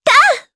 Yuria-Vox_Attack4_jp.wav